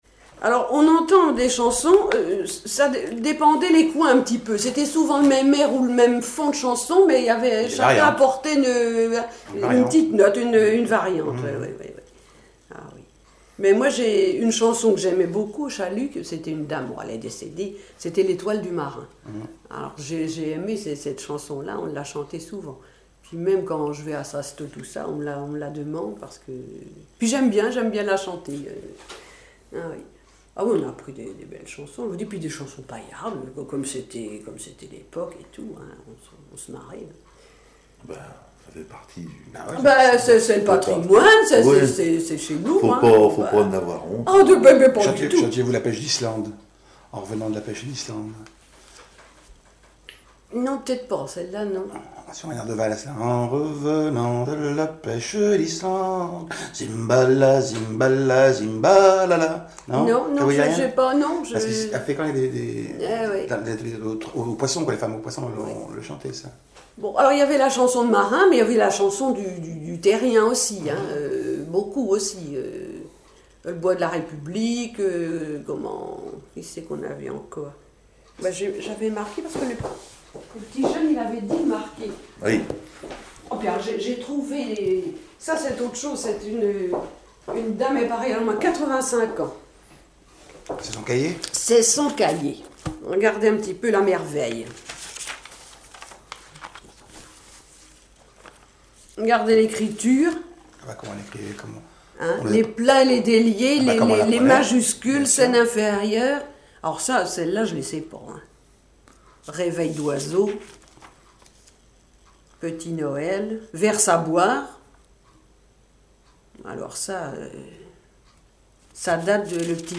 chanteur(s), chant, chanson, chansonnette
Chansons et commentaires